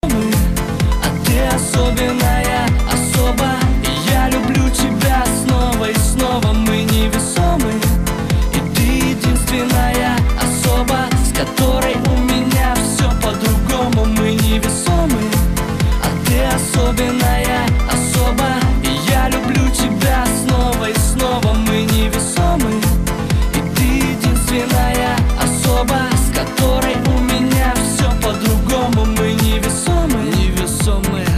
• Качество: 190, Stereo
мужской вокал